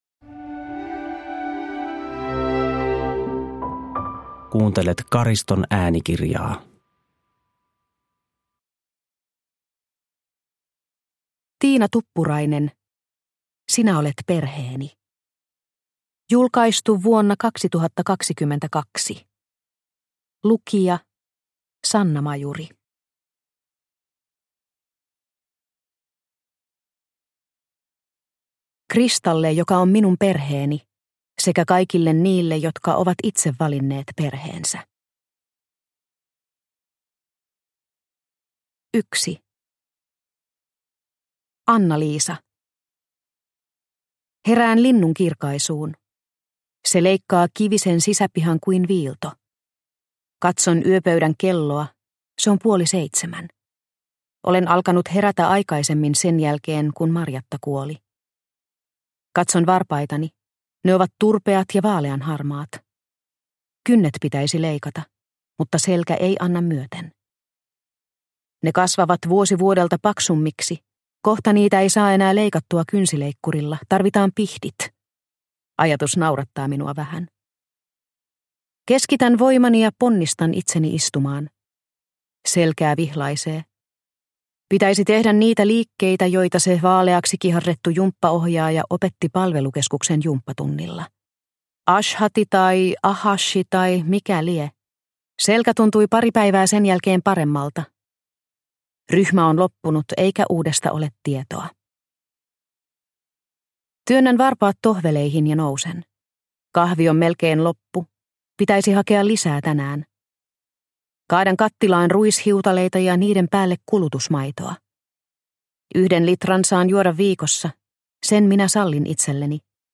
Sinä olet perheeni – Ljudbok – Laddas ner